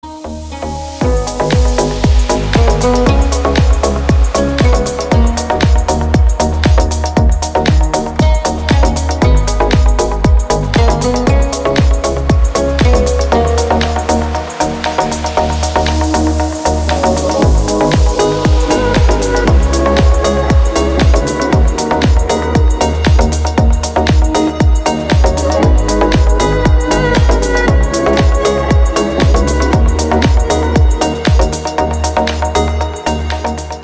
без слов мелодичные